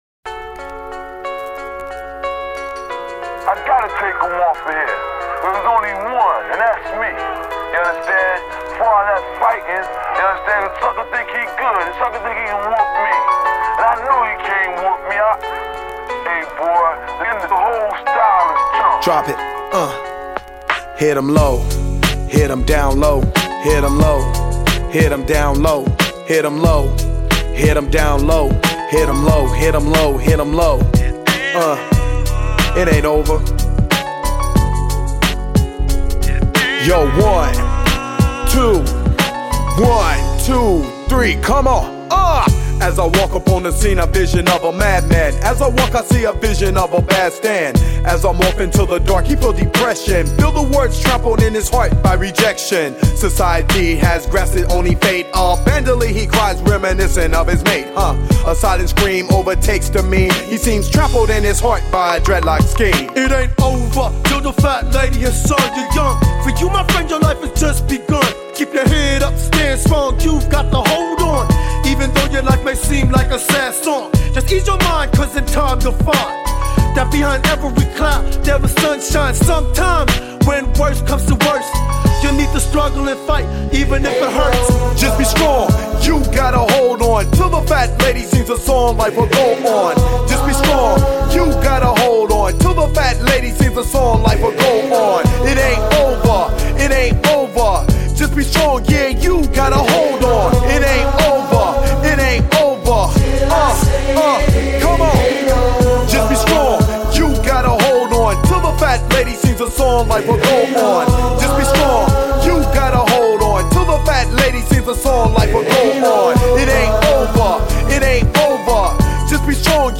Жанр: rap
Рэп Хип-хоп.